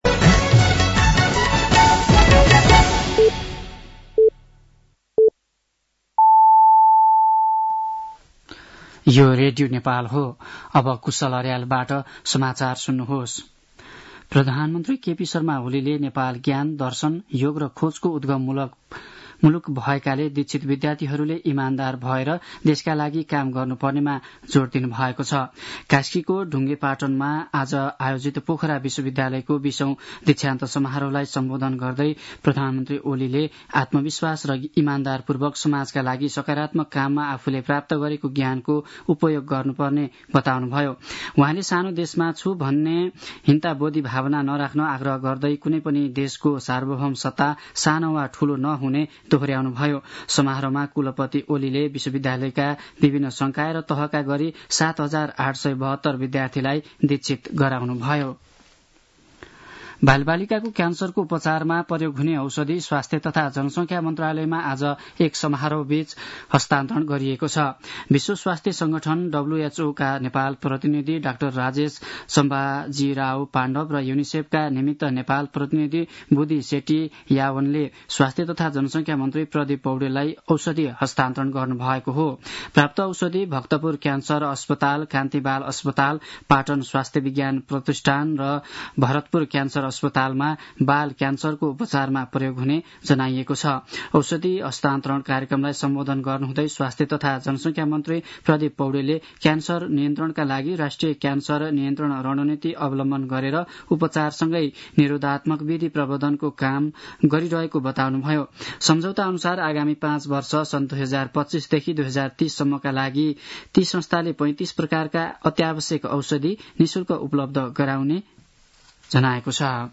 साँझ ५ बजेको नेपाली समाचार : ९ फागुन , २०८१
5-pm-news-3.mp3